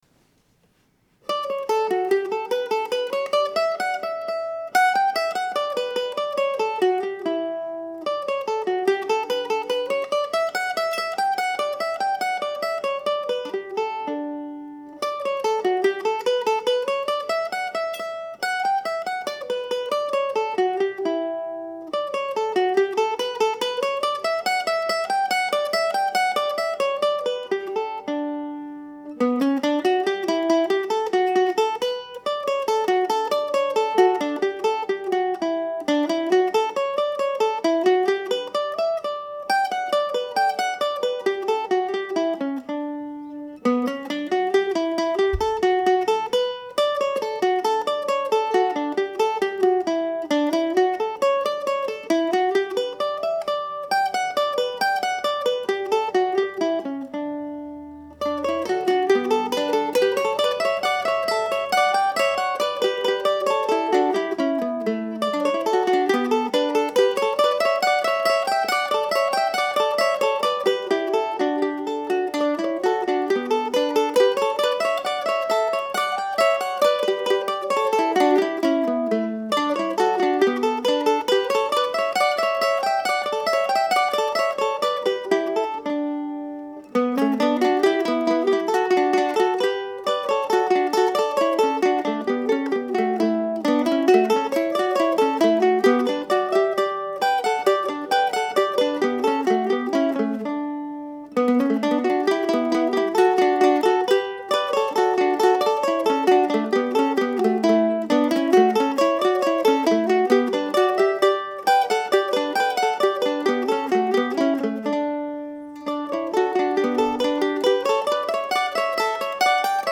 A few days ago I finally got around to recording it and here it is played on two mandolins. (Well actually just one mandolin, overdubbed.)